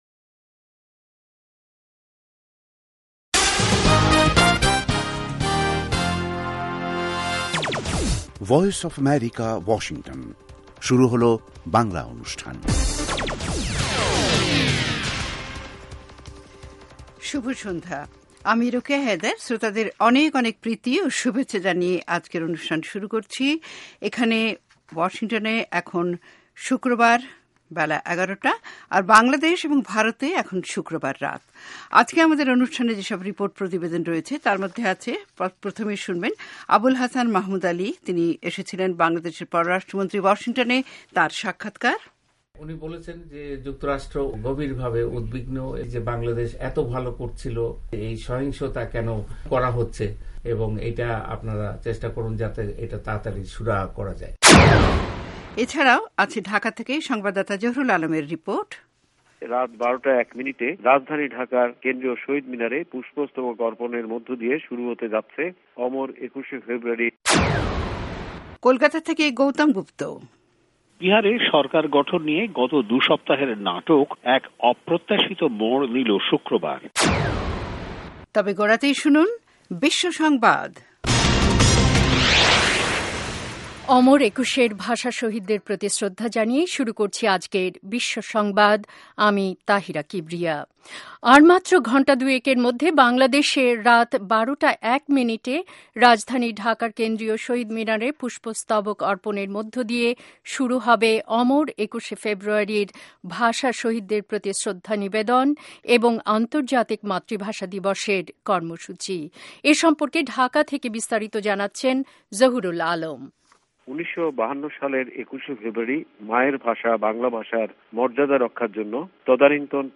অনুষ্ঠানের শুরুতেই রয়েছে আন্তর্জাতিক খবরসহ আমাদের ঢাকা এবং কলকাতা সংবাদদাতাদের রিপোর্ট সম্বলিত ‘বিশ্ব সংবাদ’, এর পর রয়েছে ওয়ার্ল্ড উইন্ডোতে আন্তর্জাতিক প্রসংগ, বিজ্ঞান জগত, যুব সংবাদ, শ্রোতাদের চিঠি পত্রের জবাবের অনুষ্ঠান 'মিতালী' এবং আমাদের অনুষ্ঠানের শেষ পর্বে রয়েছে যথারীতি সংক্ষিপ্ত সংস্করণে বিশ্ব সংবাদ।